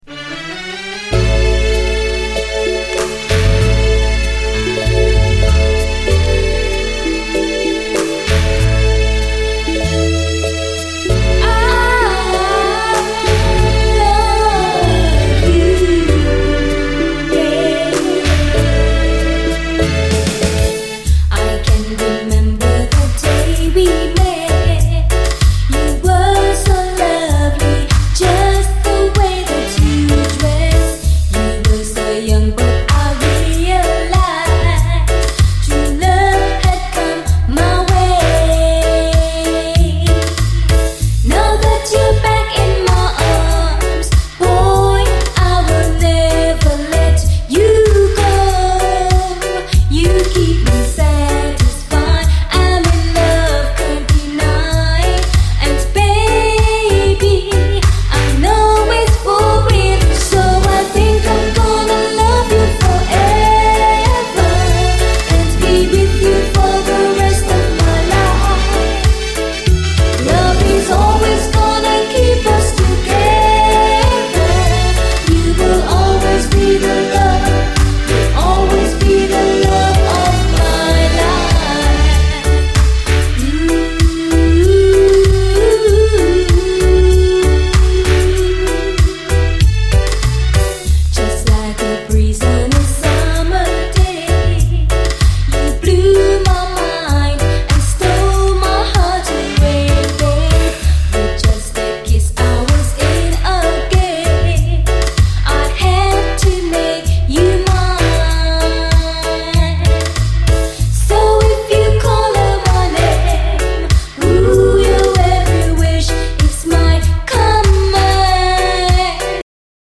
REGGAE
バーミンガム産のド・マイナーにして、切なさ甘酸っぱさがスペシャルな90’SガーリーUKラヴァーズ！